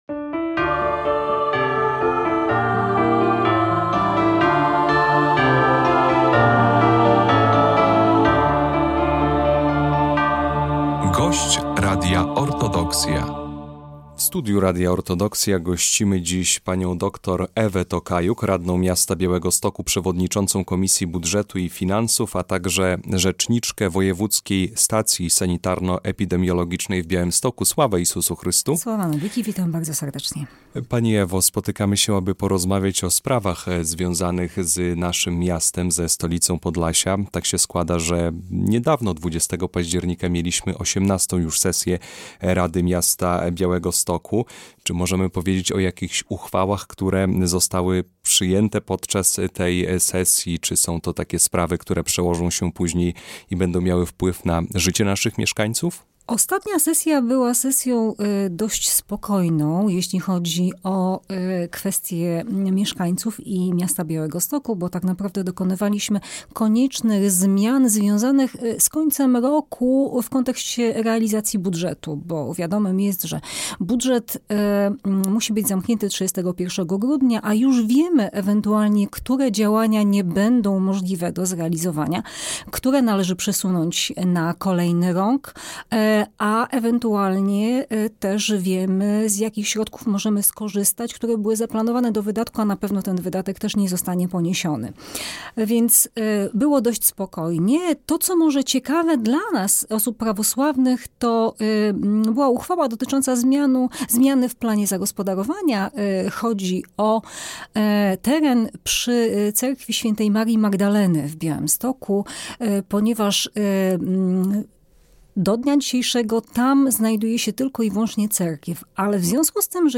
Jak rozwija się Białystok? Rozmowa z dr Ewą Tokajuk o mieszkańcach i inicjatywach lokalnych
W Radiu Orthodoxia gościliśmy dr Ewę Tokajuk, radną Miasta Białegostoku i rzecznika Wojewódzkiej Stacji Sanitarno-Epidemiologicznej. Rozmawiamy o najważniejszych tematach życia miasta: decyzjach z ostatniej sesji Rady Miasta, rekrutacji do żłobków, kondycji szkolnictwa wyższego oraz o prawosławnych inicjatywach, które wzbogacają kulturalny i społeczny krajobraz Białegostoku.